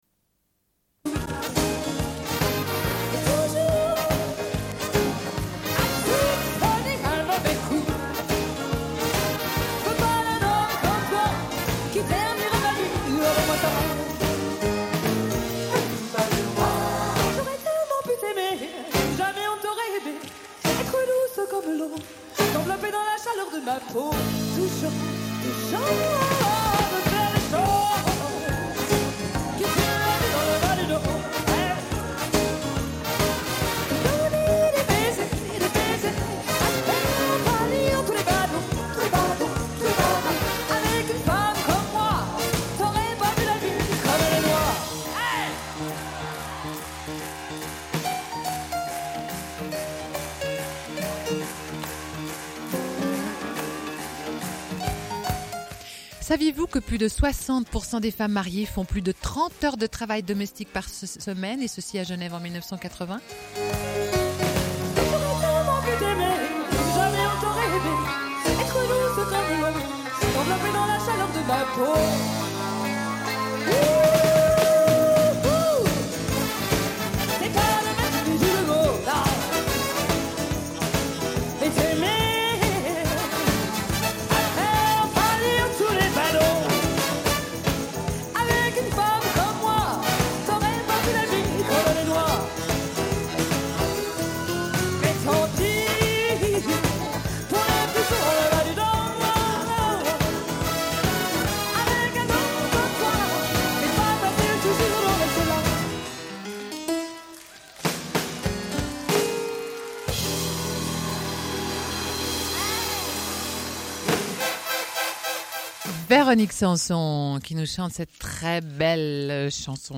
Une cassette audio, face B00:29:13
00:01:55 - Lecture d'articles sur la grève des femmes dans la Tribune de Genève.